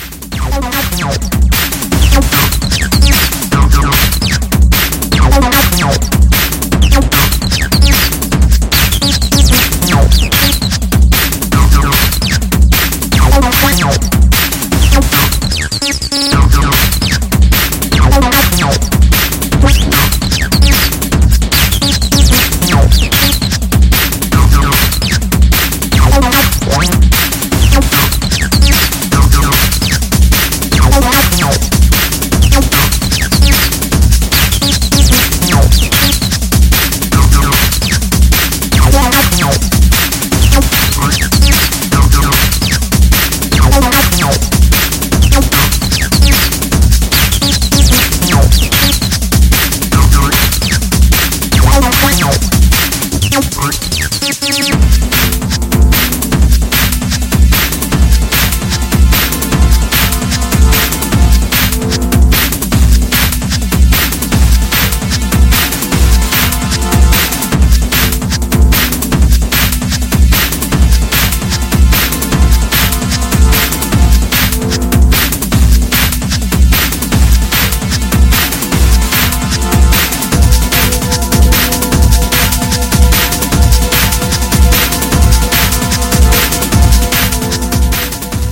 Techno 12 Inch Ep